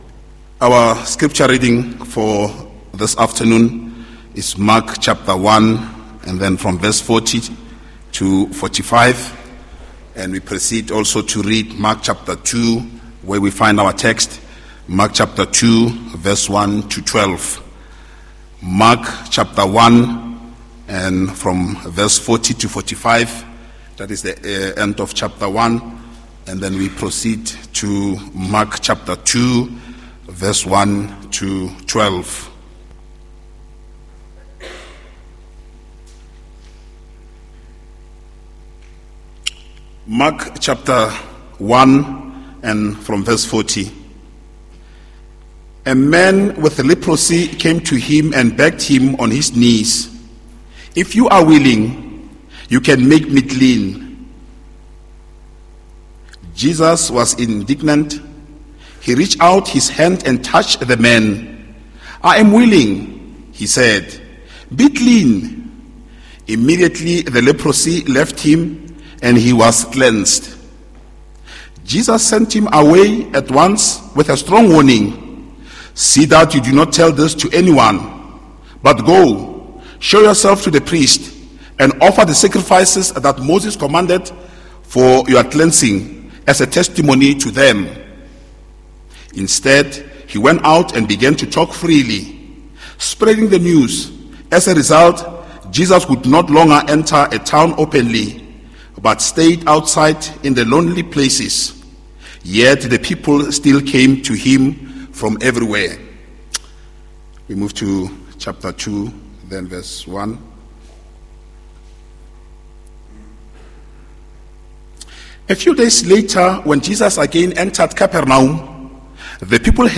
2023-10-15 Text: Markus 2: 1-12 Audio Link: Link Preek Inhoud